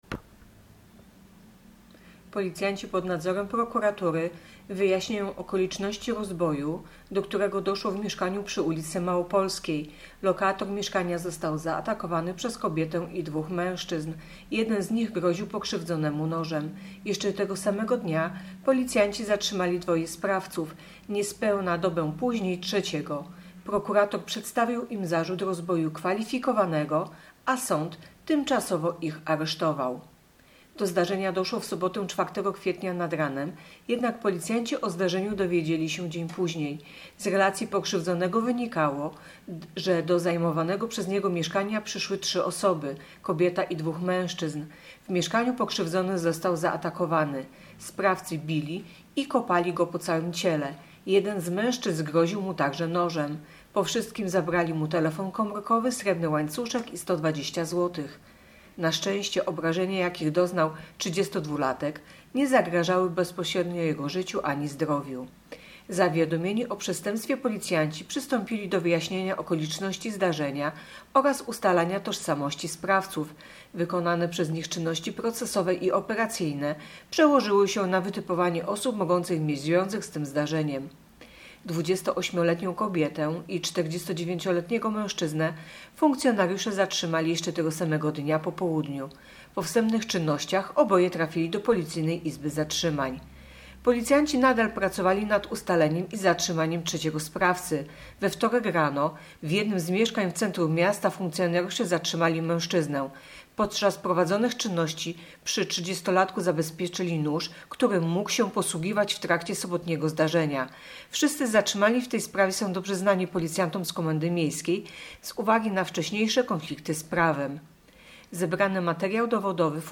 Opis nagrania: Nagranie informacji pt. Policjanci zatrzymali trzy osoby podejrzane o rozbój.